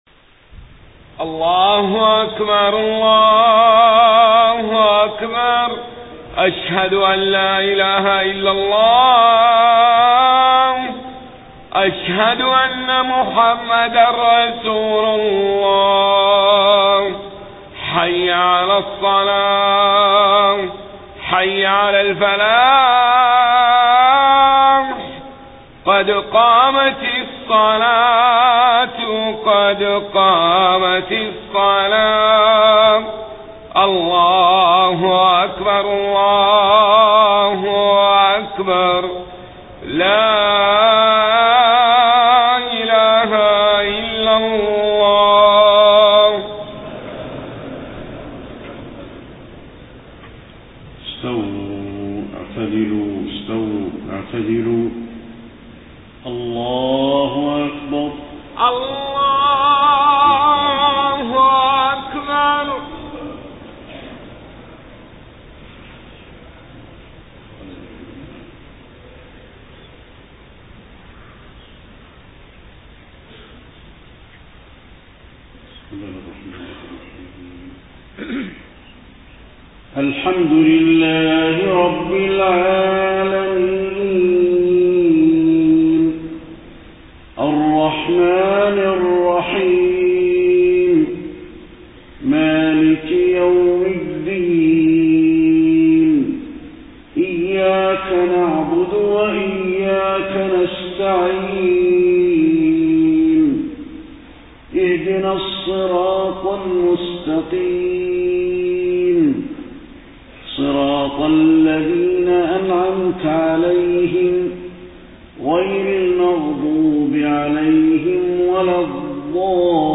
صلاة العشاء 5 ربيع الأول 1431هـ خواتيم سورة الدخان 38-59 > 1431 🕌 > الفروض - تلاوات الحرمين